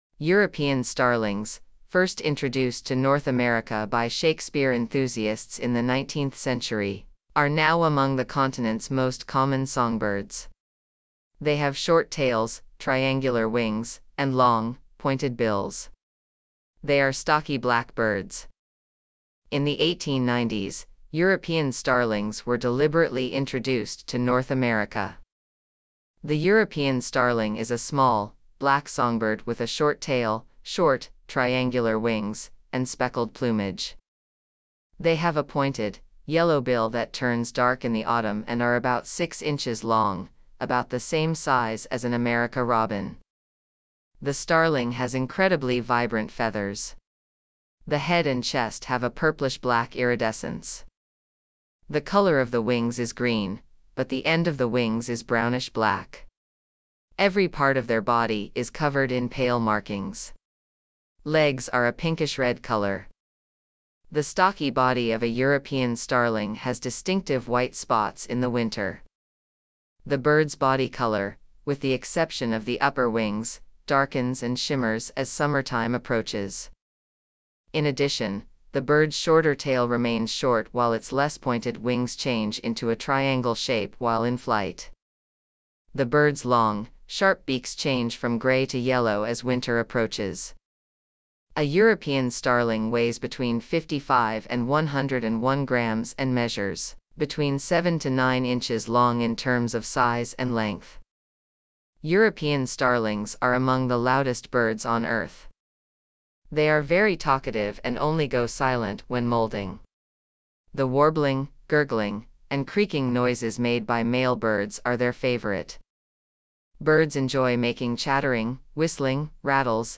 European Starling
European Starlings are among the loudest birds on Earth.
The warbling, gurgling, and creaking noises male birds make are their favorite.
• Birds enjoy making chattering, whistling, rattles, and liquid noises. European Starlings can accurately mimic the sounds of other birds and are skilled mimics.
European-Starling.mp3